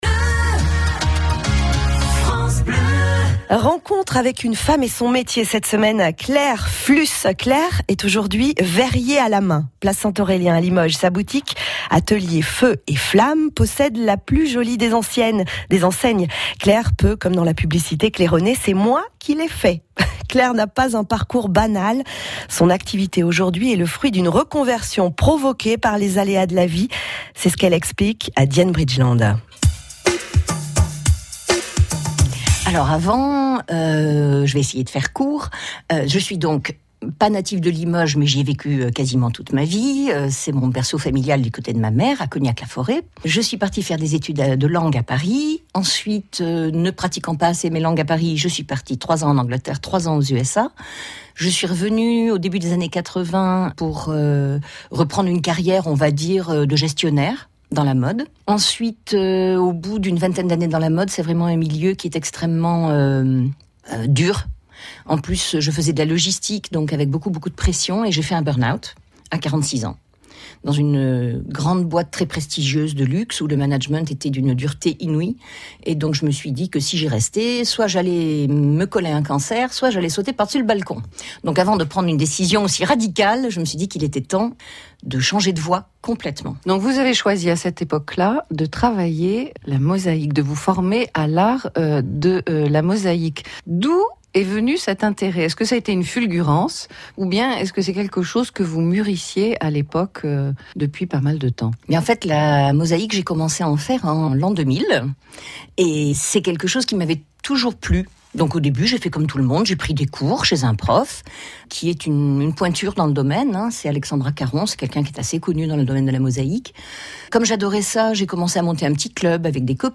J’ai été interviewée fin mars par France Bleu.